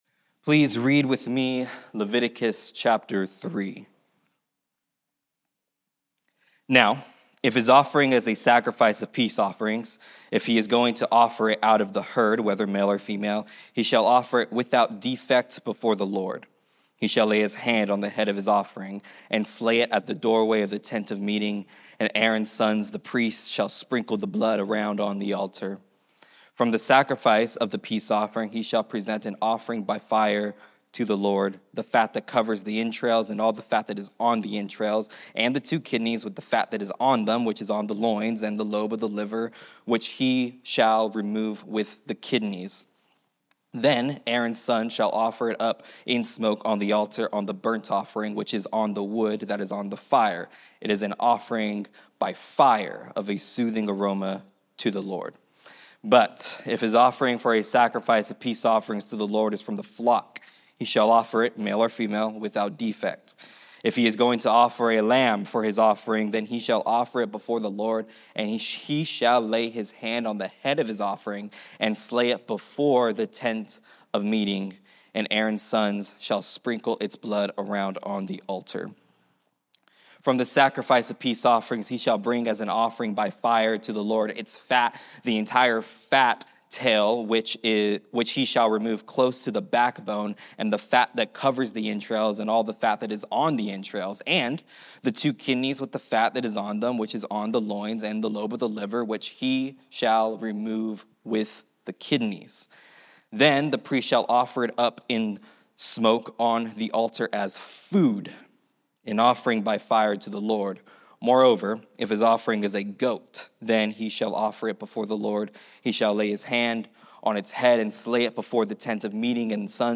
Mission Hills, California.